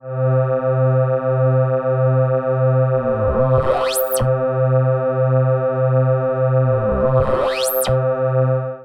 beamOld.wav